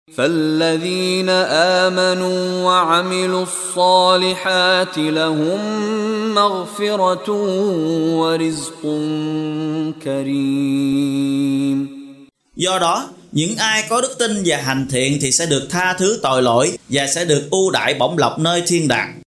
Đọc ý nghĩa nội dung chương Al-Hajj bằng tiếng Việt có đính kèm giọng xướng đọc Qur’an